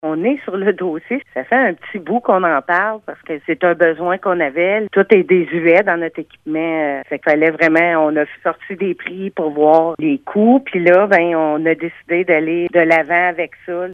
Également, l’achat de certains appareils électroniques a été réalisé. La mairesse de Bois-Franc, Julie Jolivette, mentionne que l’équipement n’était plus adéquat :